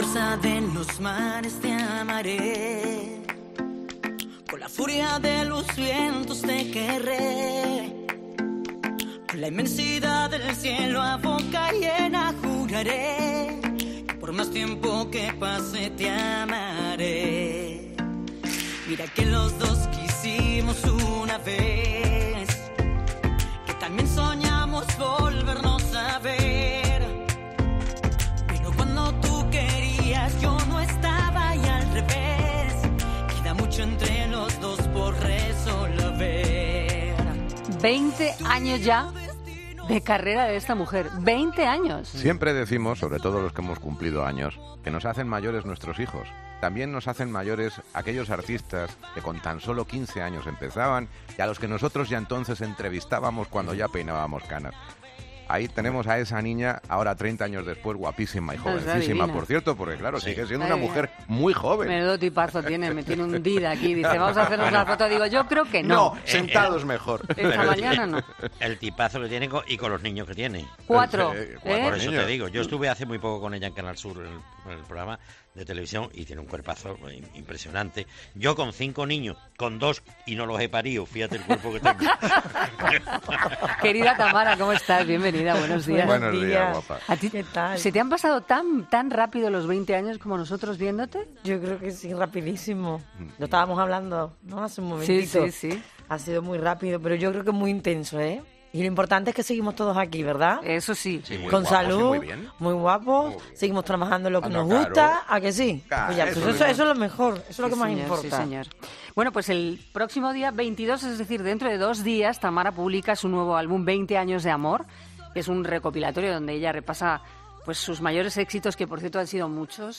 Este miércoles ha presentado en 'Herrera en COPE' su nuevo disco, '20 años de amor', donde hace un repaso por su trayectoria.